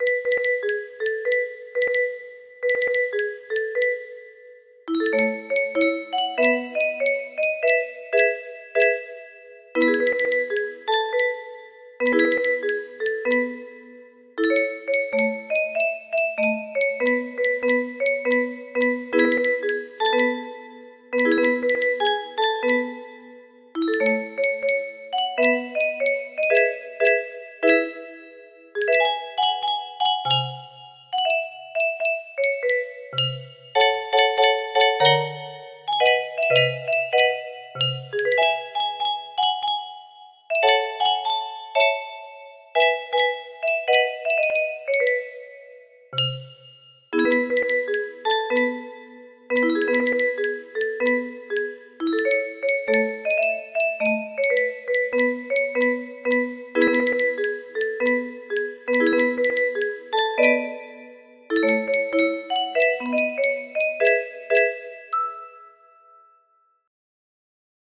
30 note music box